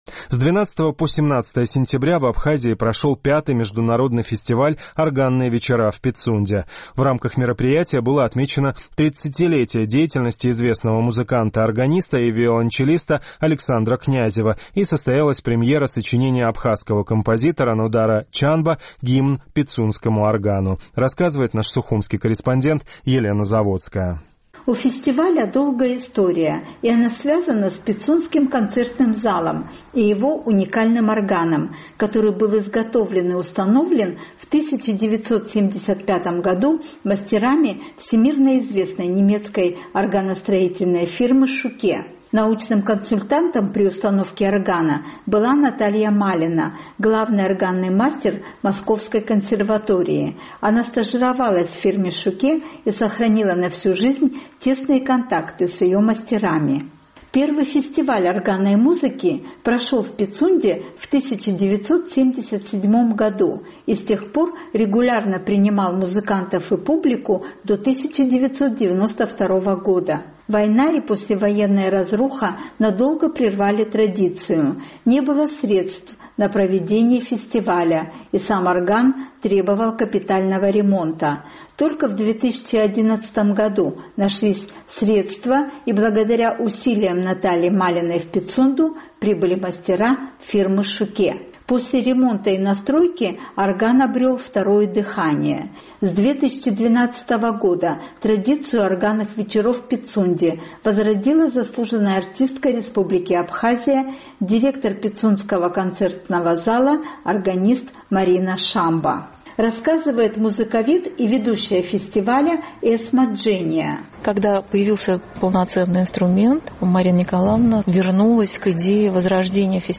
Вот так прозвучало на органе, самом крупном на постсоветском пространстве, в древнем Пицундском храме «Либертанго» известного аргентинского композитора Астора Пьяццоллы